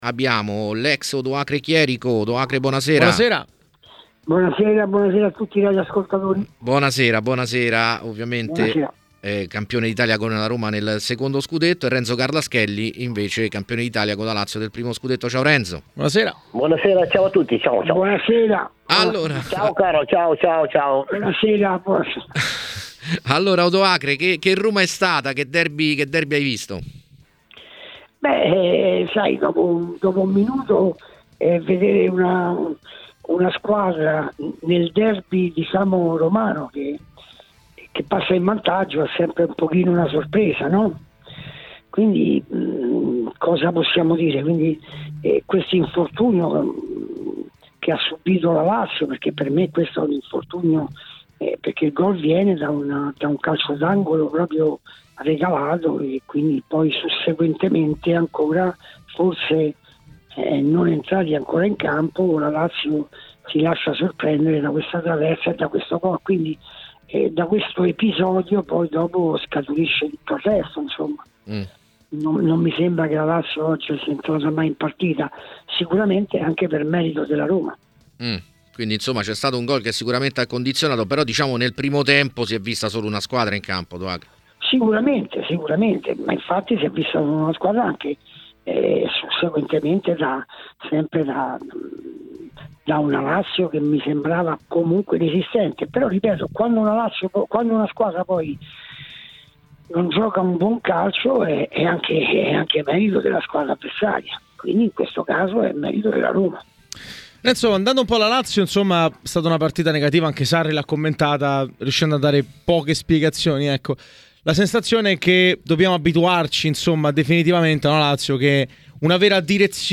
è intervenuto oggi ai microfoni di TMW Radio per commentare il derby di Roma: